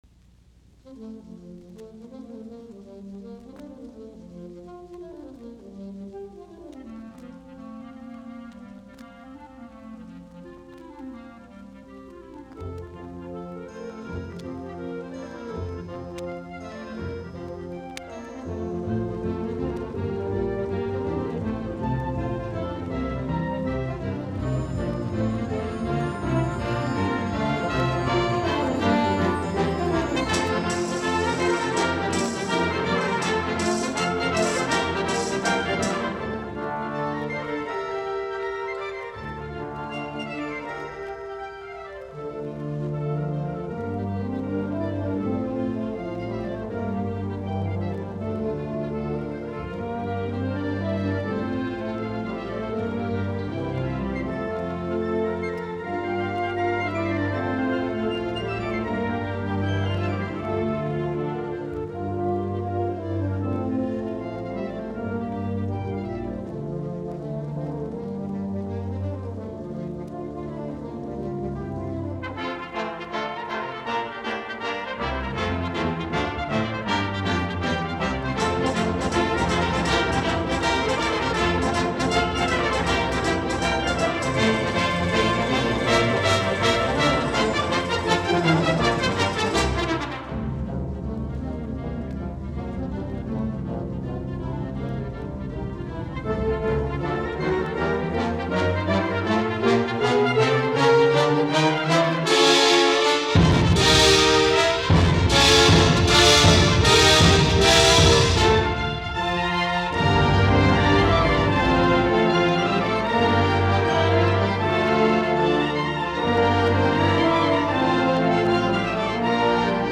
Soitinnus: Puhallinorkesteri.